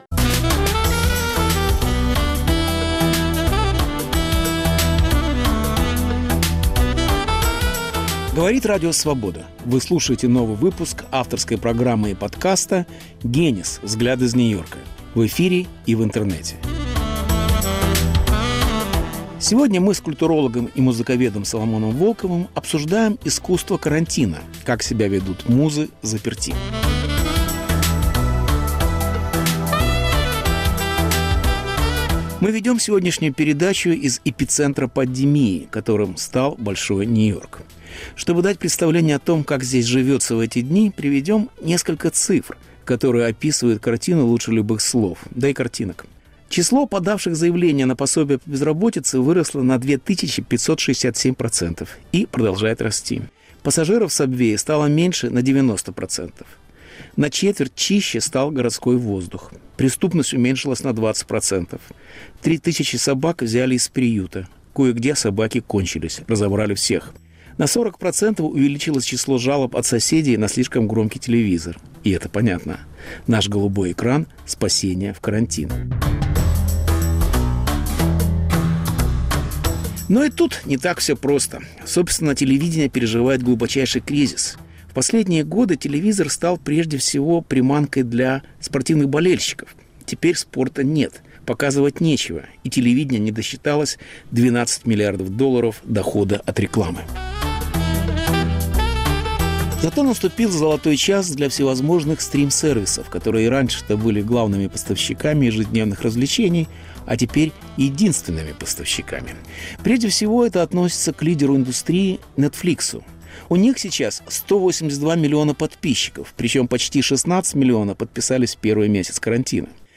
Беседа с Соломоном Волковым о старом кино и виртуальных музеях. Кинематограф без большого экрана Эскапизм прежнего Голливуда Плюсы и минусы музея на диване